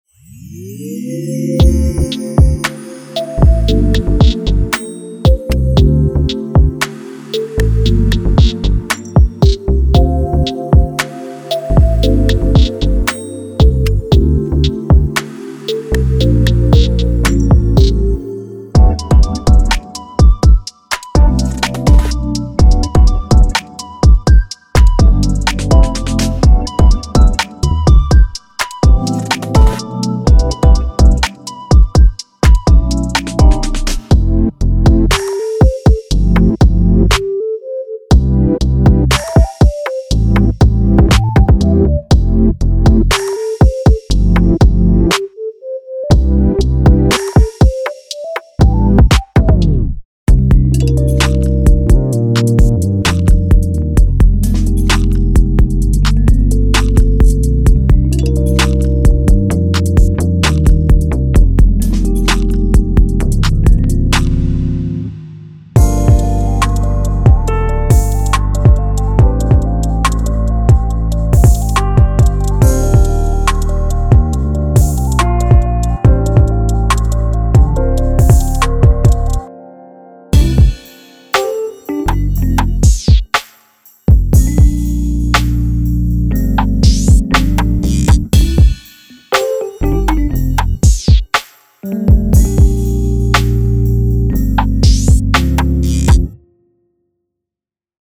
Genre:Future RnB
穏やかで夢のような雰囲気から、明るく深みのあるソウルフルなものまで幅広くカバー。
90年代や2000年代のクラシックなR&Bの雰囲気から、モダンR&Bの洗練されたサウンドまで、その本質を捉えています。
ご注意ください：デモ内のドラム、ベース、シンセサウンドは本パックには含まれていません。
デモサウンドはコチラ↓
92 RnB Keys Loops
Tempo/Bpm 115-140